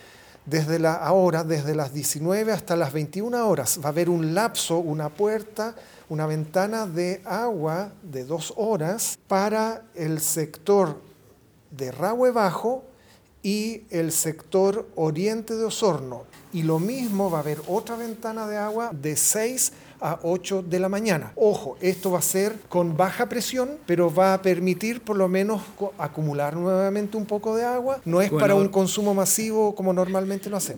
Durante las horas de la tarde noche del jueves, las autoridades dieron un nuevo punto de prensa, en donde el gobernador subrogante de Osorno, Alex Meeder, indicó que el jueves se abrió el suministro en una ventana de dos horas para que las personas pudieran recolectar agua potable.